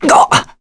Roi-Vox_Damage_kr_01.wav